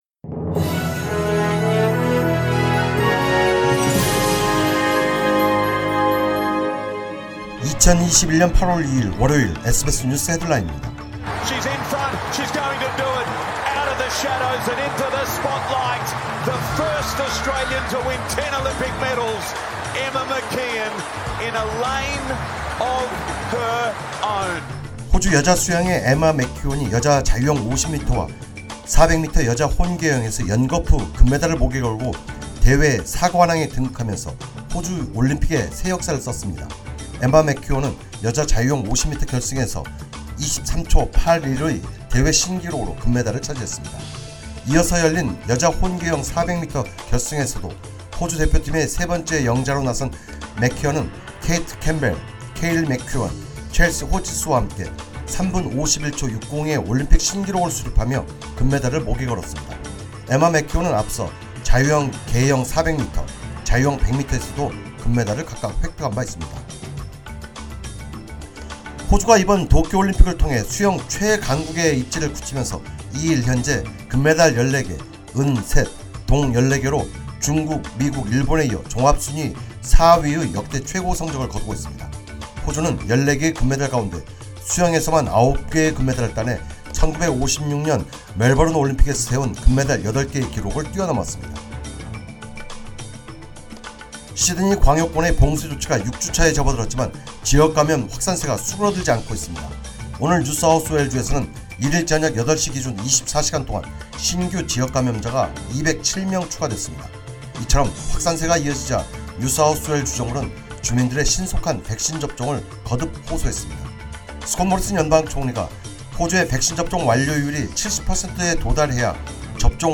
2021년 8월 2일 월요일 SBS 뉴스 헤드라인입니다.